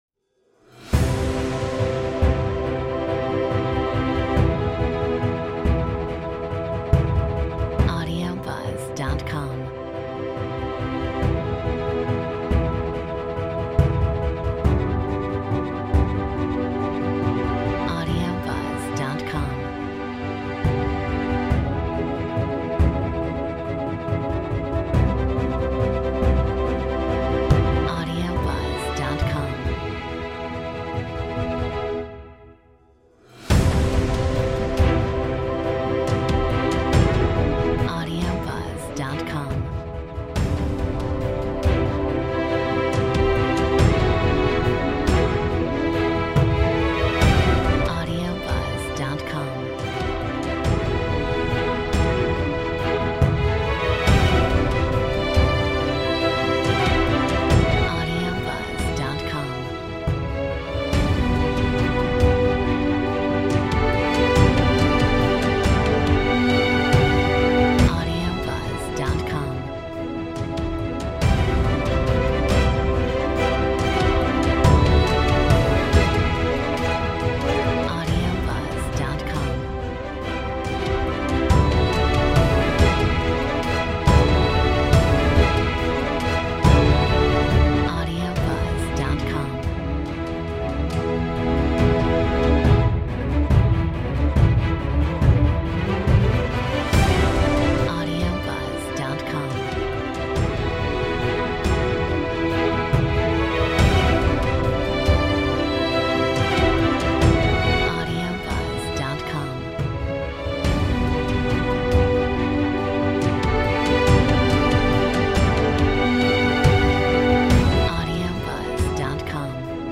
Metronome 70